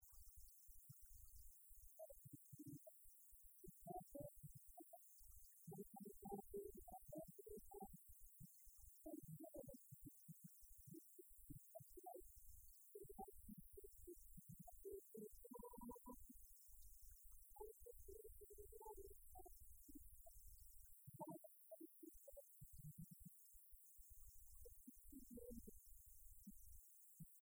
strophique
Concert de la chorale des retraités
Pièce musicale inédite